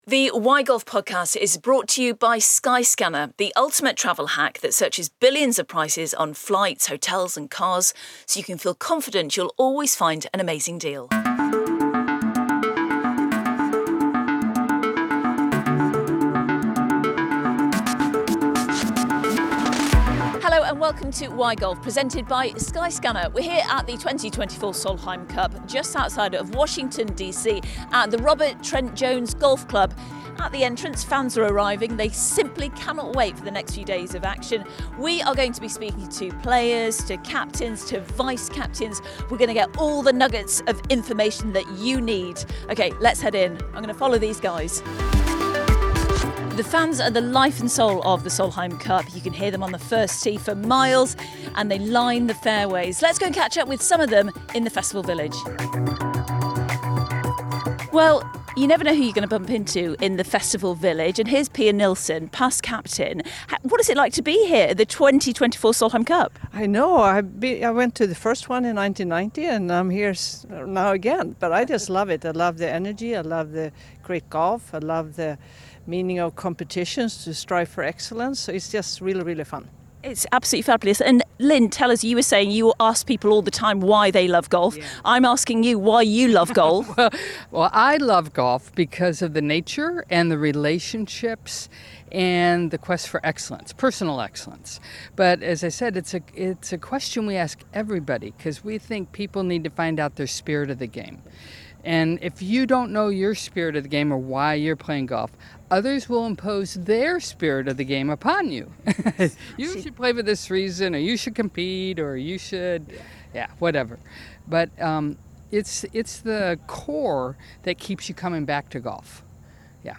Why Golf comes to you this week from the Solheim Cup in Virginia, USA!